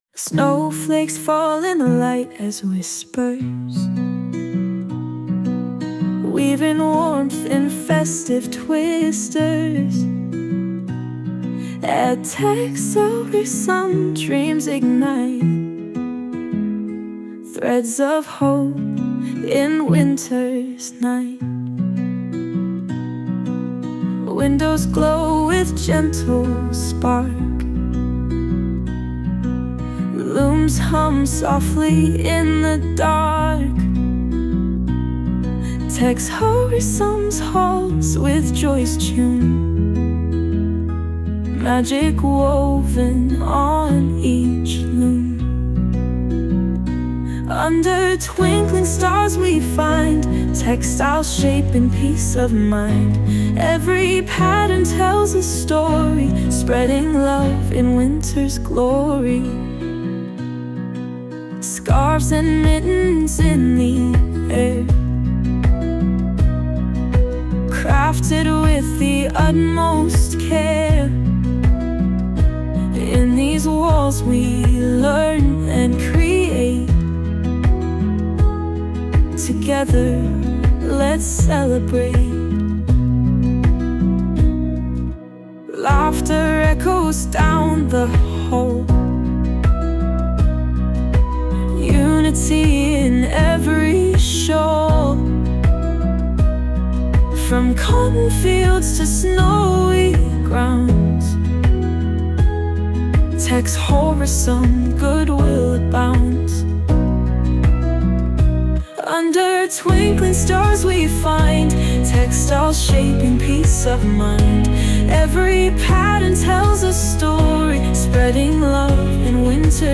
4. Advent: Der TEXOVERSUM-Weihnachtssong! 🎶🎄
Zum krönenden Abschluss unserer Adventsaktion erwartet euch am 4. Advent eine ganz besondere Überraschung: "A Christmas at TEXOVERSUM", unser exklusiver Weihnachtssong (powered by Suno), der die festliche Stimmung perfekt einfängt!